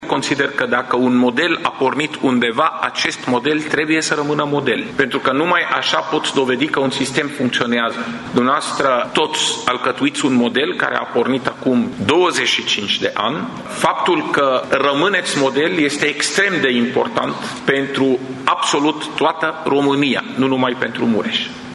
SMURD Tîrgu-Mureș rămâne un model la nivel național, după 25 de ani de la lansarea serviciului, spune fondatorul acestuia, Raed Arafat, prezent astăzi la evaluarea activității ISU: